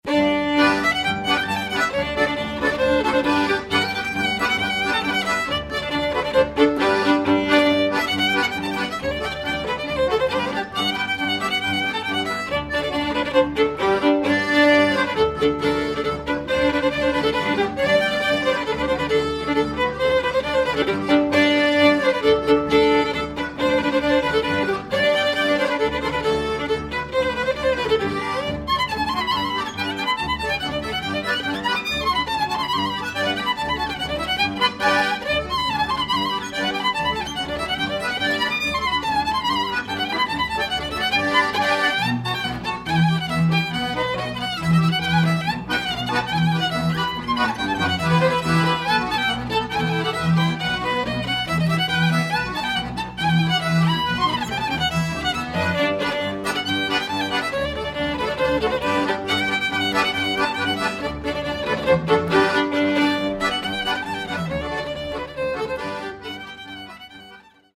Violin
Button Accordion, Tsimbl
Cello, Tilinca, Baraban
Genre: Klezmer.